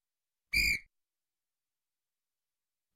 Вы можете скачать или слушать онлайн резкие, громкие и предупреждающие сигналы, которые подойдут для монтажа видео, создания спецэффектов или использования в мобильных приложениях.
Современный полицейский свисток